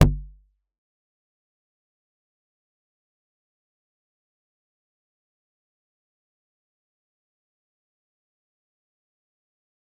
G_Kalimba-C1-f.wav